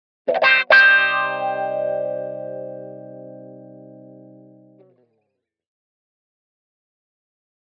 Guitar Thin Wah-Wah Finale Chord - Double